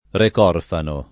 Recorfano [ rek 0 rfano ]